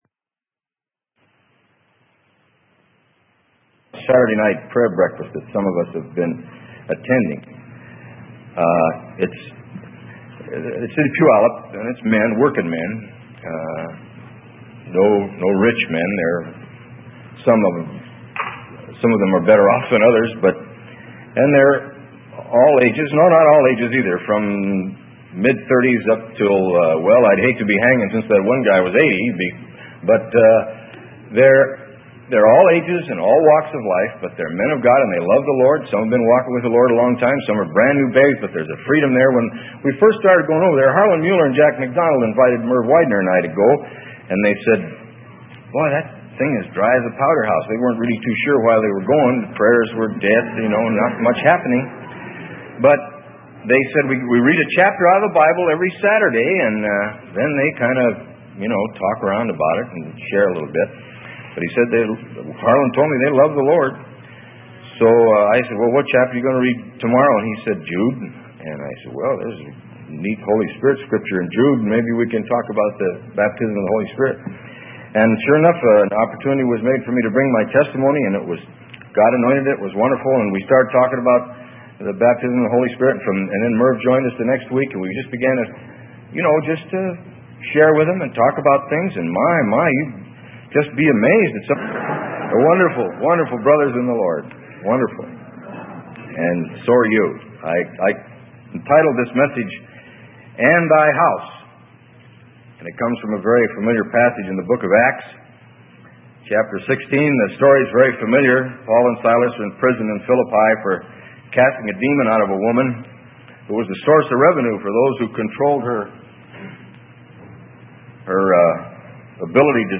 Sermon: And Thy House - Freely Given Online Library